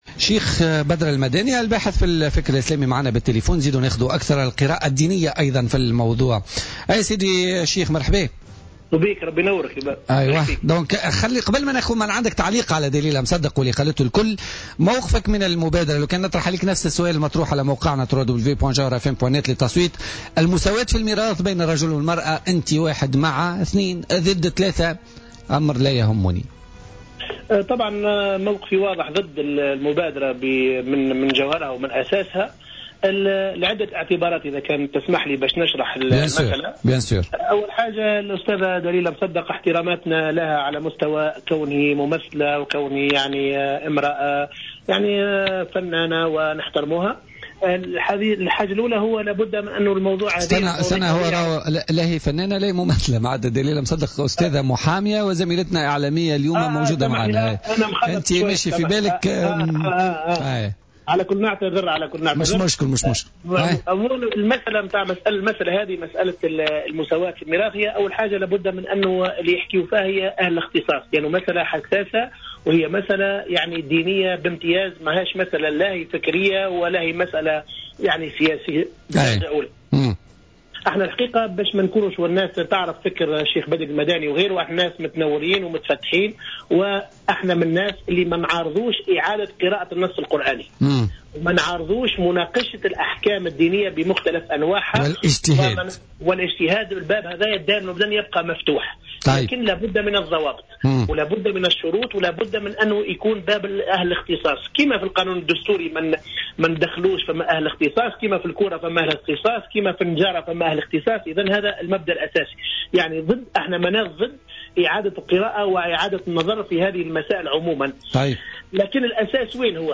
خلال مداخلته في برنامج "بوليتيكا" اليوم